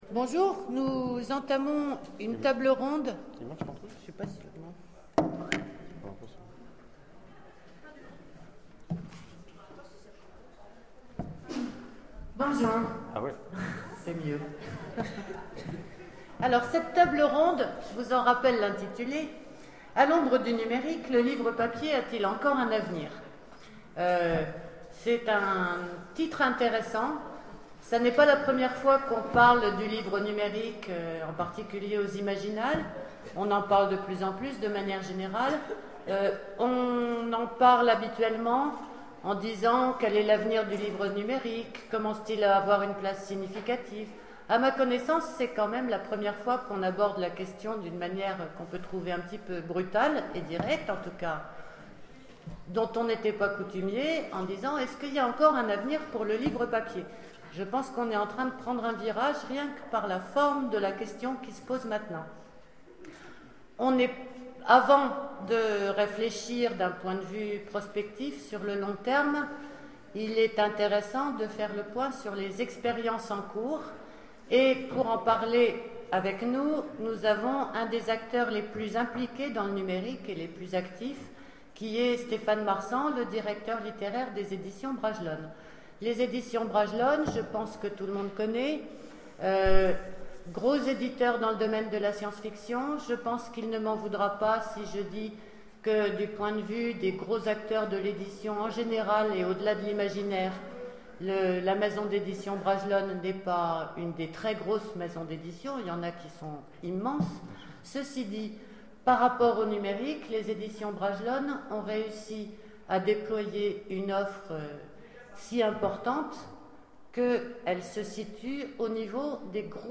Imaginales 2012 : Conférence A l'ombre du numérique